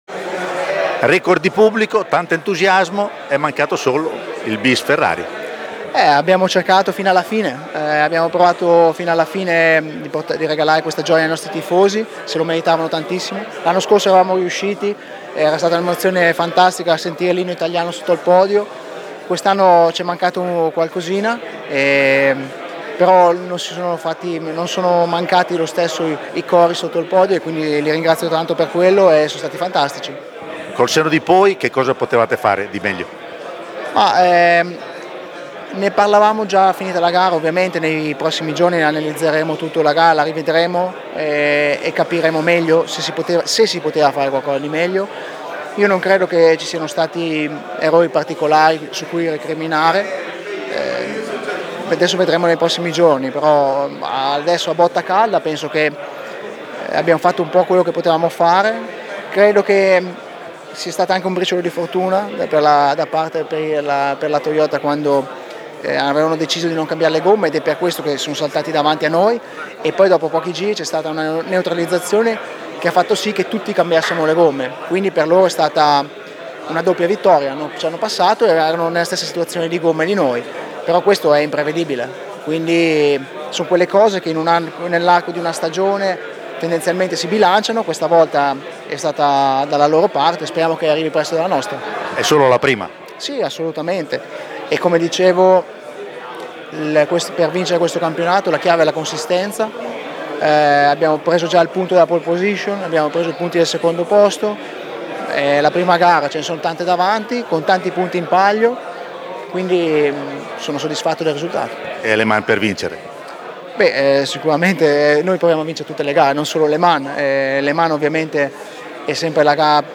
Alessandro Pier Guidi, vincitore con la Ferrari 499/P della passata edizione